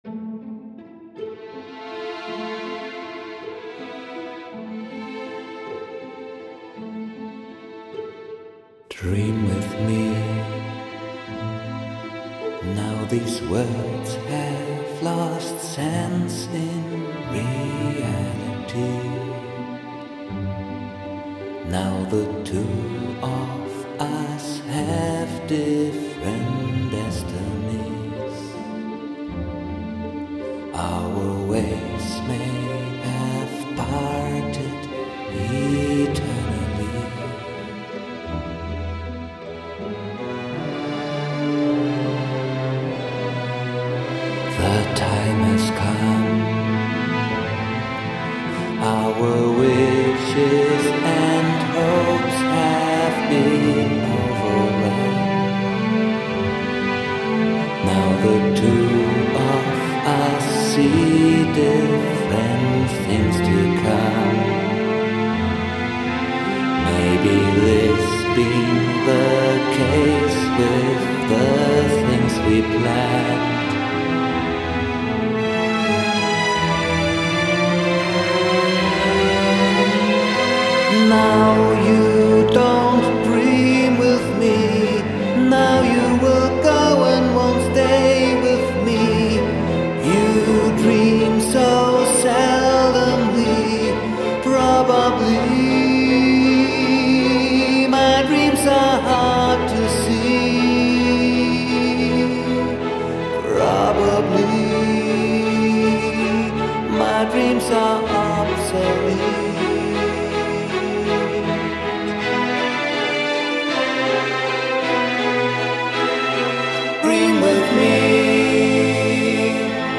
Another of my songs from the early 90ies, which was later refurbished with virtual orchestra and proper recording.
In my kind-of classical tradition, the orchestral line was progressivly composed through the whole piece for some more drama.